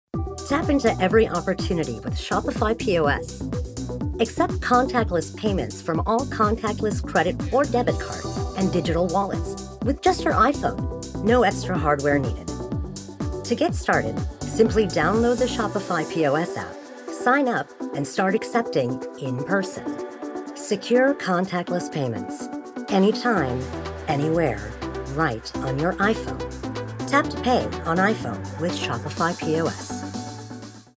Online Advertisement
I have a naturally rich, deep voice that exudes confidence while maintaining authenticity and relatability.